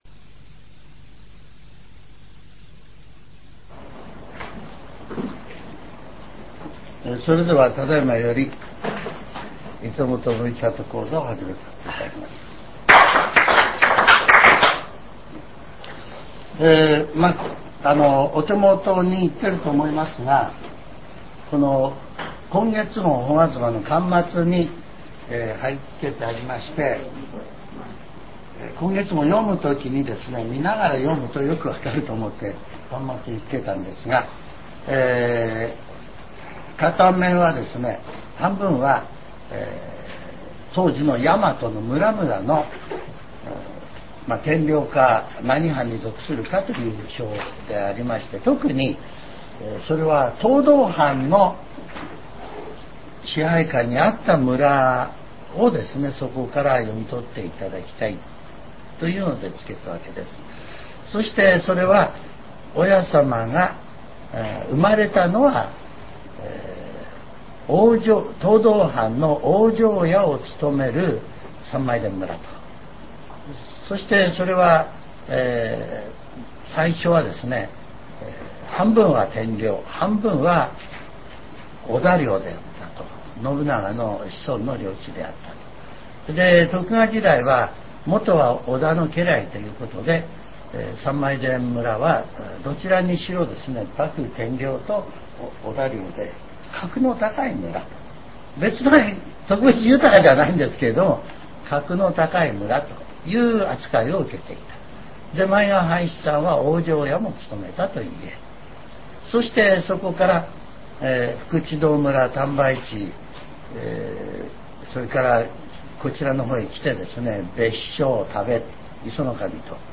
全70曲中11曲目 ジャンル: Speech